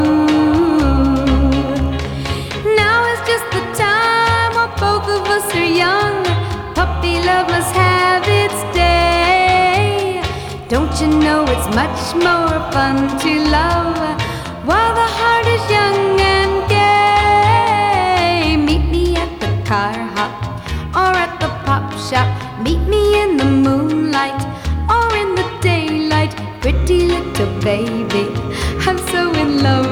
Скачать припев
Vocal